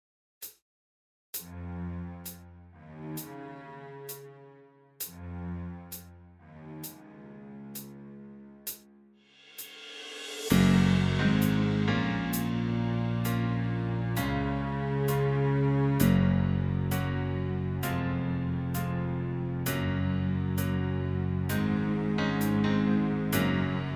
Minus All Guitars Pop (2010s) 4:05 Buy £1.50